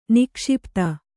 ♪ nikṣipta